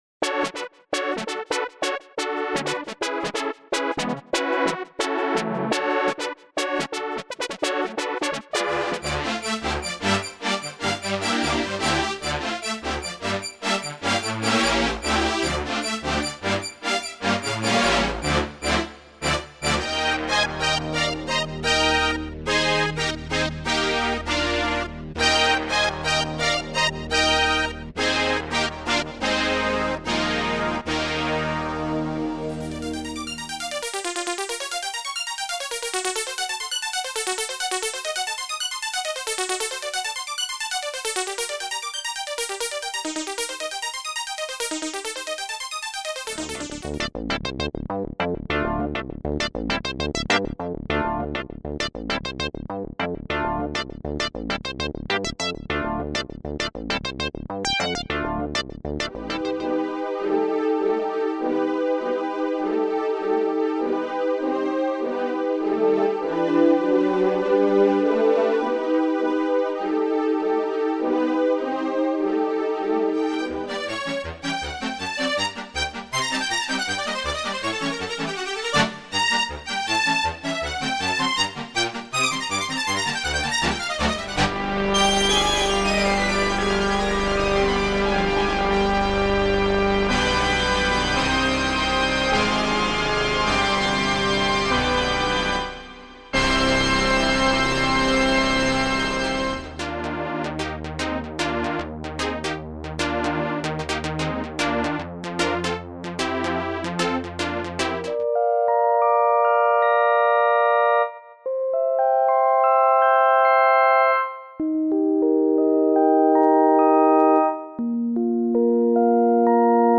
nord library Synthex Nord Library Free library samples
only for Nord synth   effet
nord demo
nord-synthex.mp3